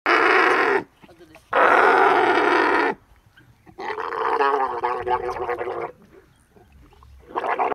Camel Sound Effects Free Download